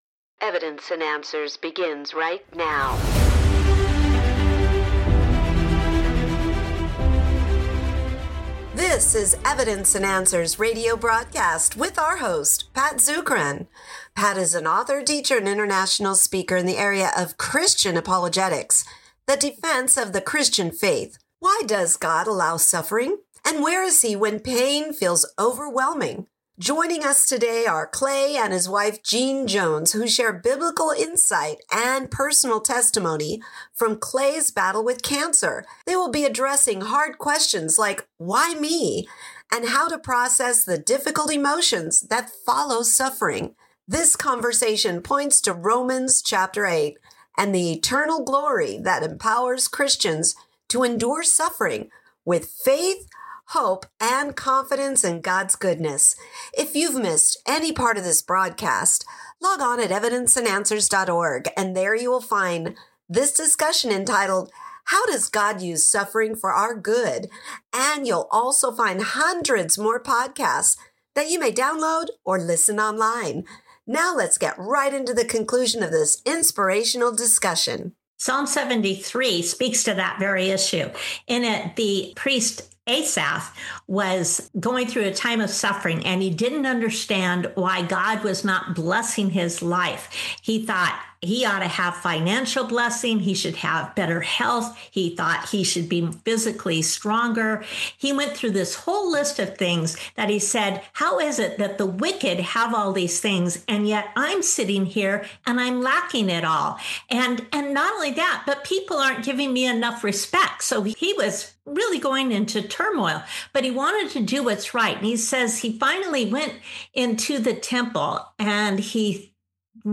This conversation points to Romans 8 and the eternal glory that empowers Christians to endure suffering with faith, hope, and confidence in God’s goodness.